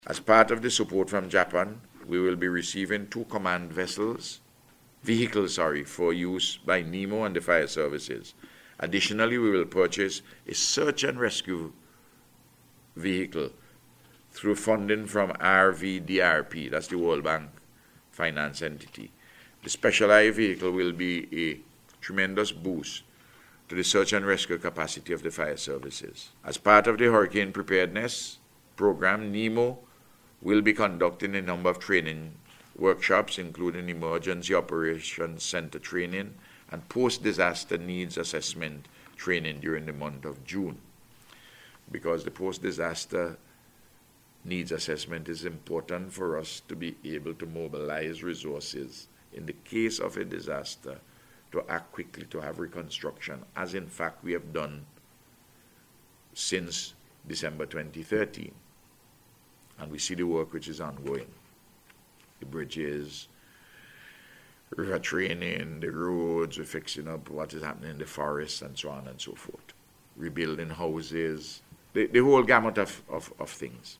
Prime Minister Dr. Ralph Gonsalves says the Japanese are donating a substantial quantity of emergency supplies to the country. Dr. Gonsalves was speaking on the start of the 2015 Atlantic hurricane season which opened on June 1st.